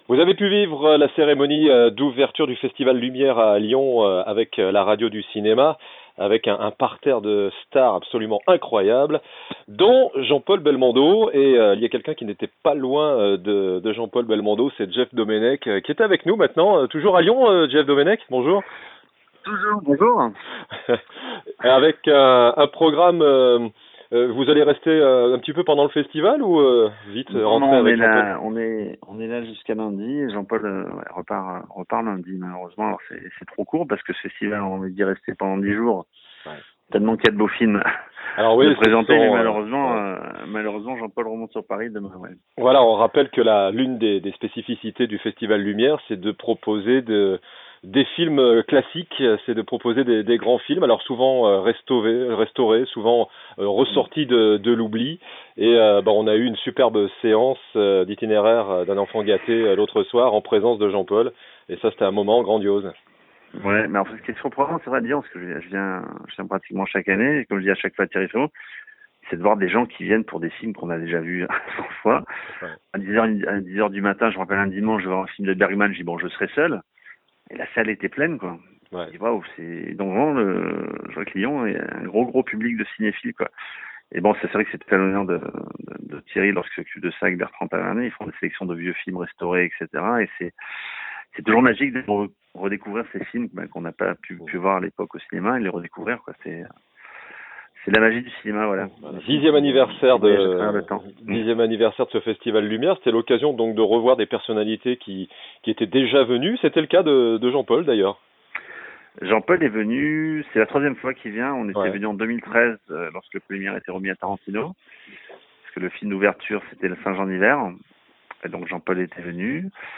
Écrivain , Réalisateur , Producteur ...